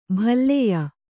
♪ bhalleya